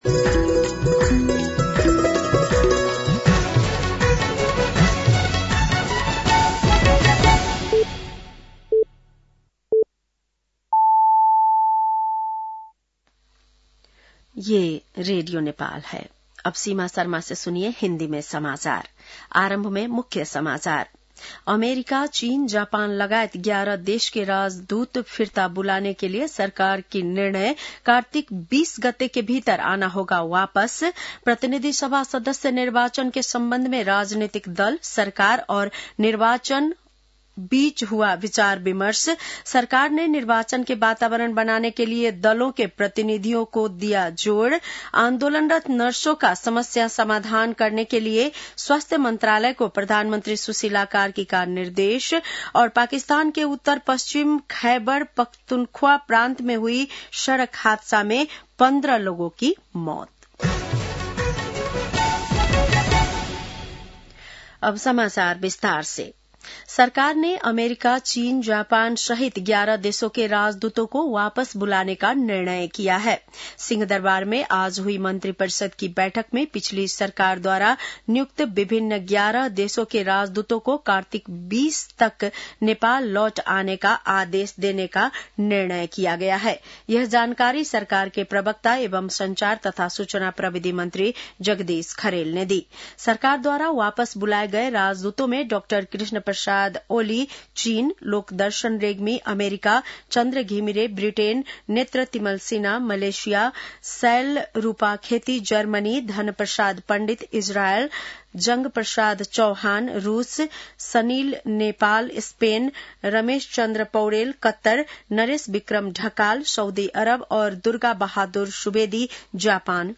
बेलुकी १० बजेको हिन्दी समाचार : ३० असोज , २०८२
10-PM-Hindi-NEWS-1-1.mp3